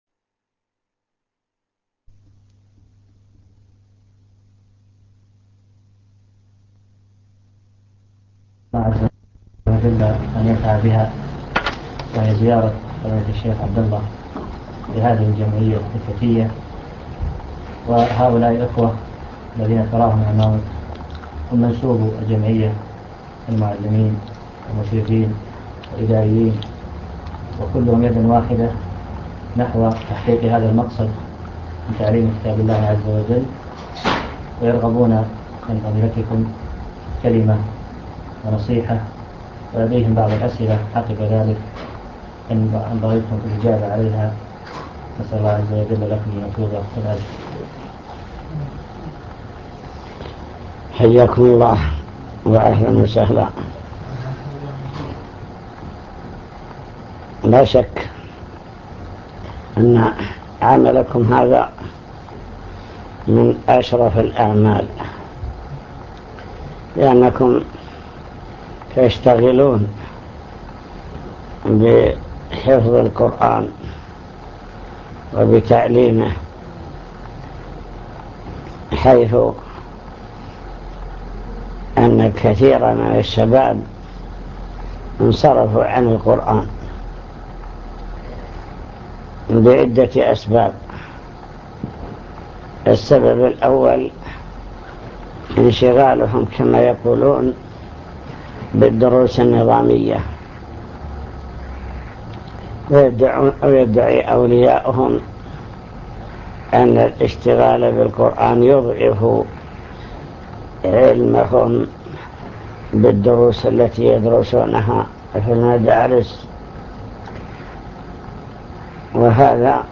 المكتبة الصوتية  تسجيلات - لقاءات  لقاء مع تحفيظ القرآن بالخرمة